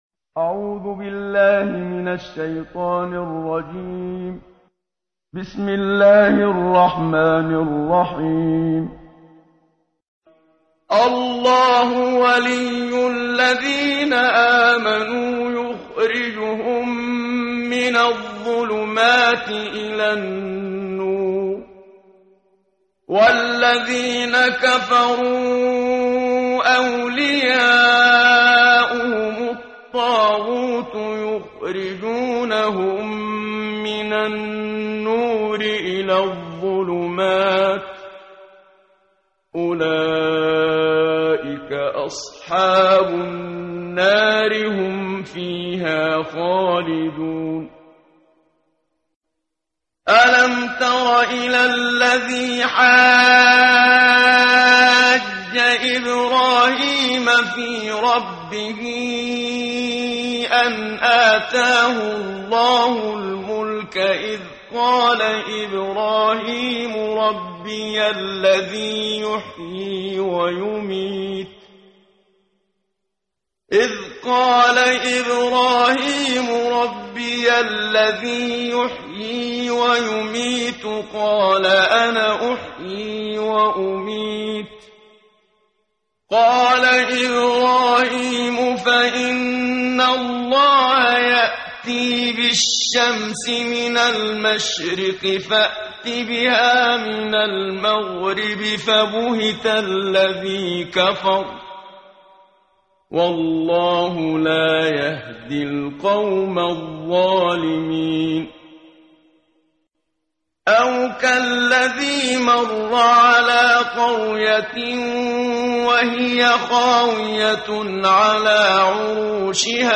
قرائت قرآن کریم ، صفحه 43، سوره مبارکه بقره آیات 257 تا 259 با صدای استاد صدیق منشاوی.